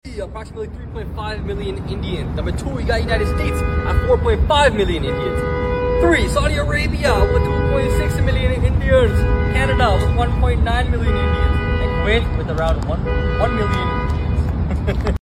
When the car alarm goes sound effects free download
When the car alarm goes off mid shoot 😂